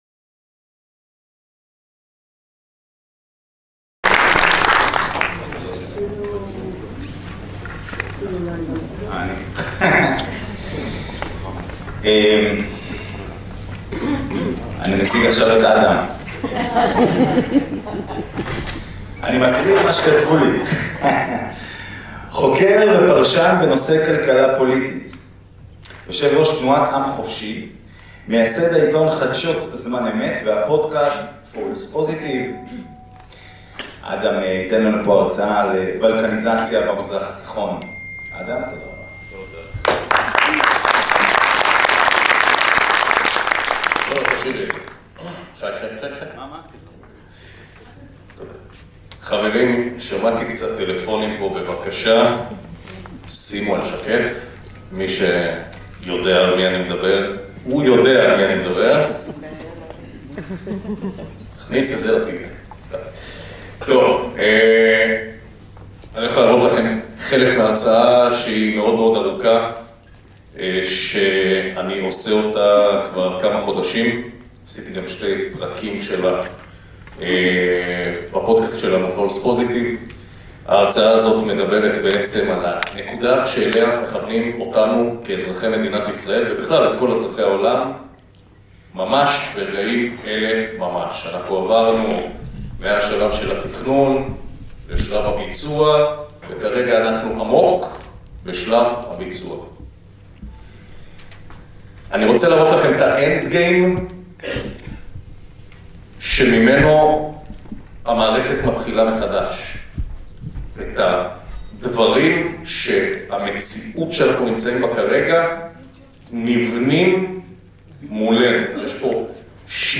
כנס רוח אחרת בלקניזציה בישראל